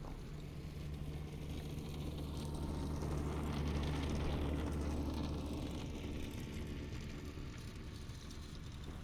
Zero Emission Subjective Noise Event Audio File (WAV)
Zero Emission Snowmobile Description Form (PDF)